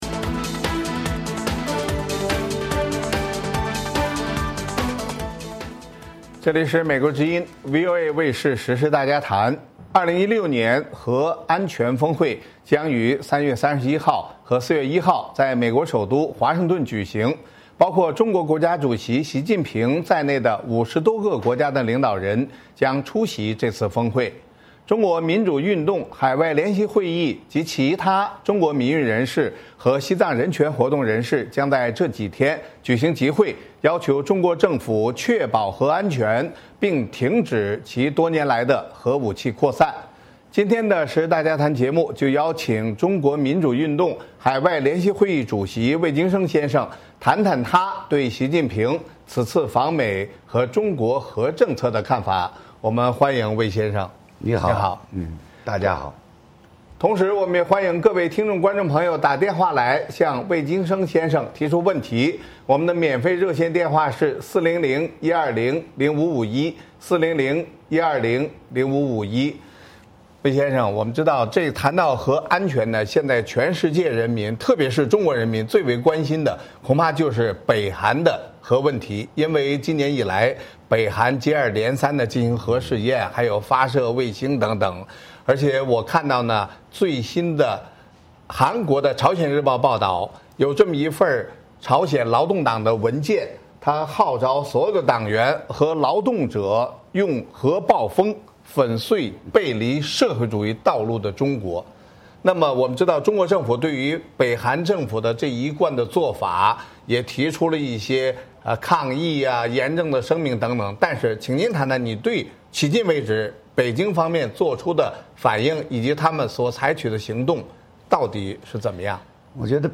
今天的时事大家谈节目邀请中国民主运动海外联席会议主席魏京生，谈谈他对习近平此次访美和中国核政策的看法。